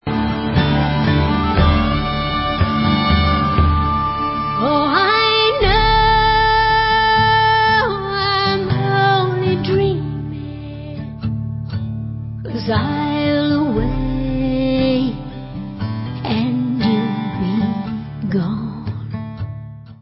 sledovat novinky v oddělení Rockabilly/Psychobilly